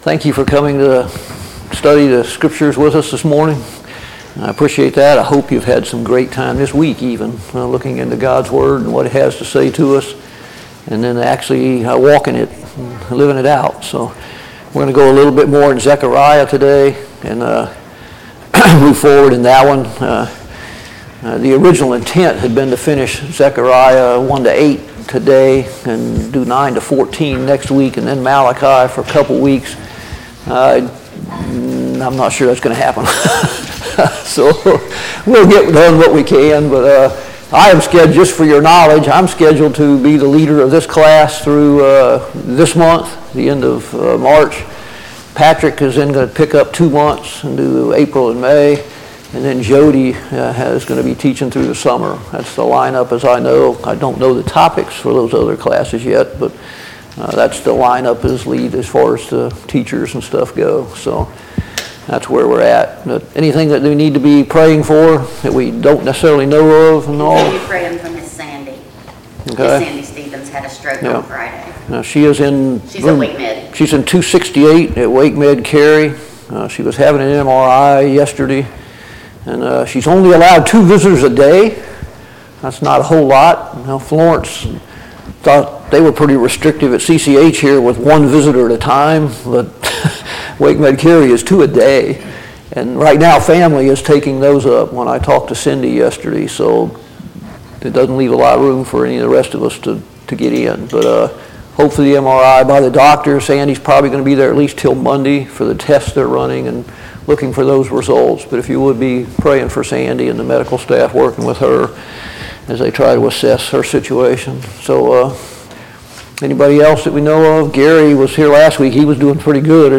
Study on the Minor Prophets Passage: Zechariah 1-8 Service Type: Sunday Morning Bible Class « 23.